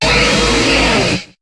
Cri de Rhinastoc dans Pokémon HOME.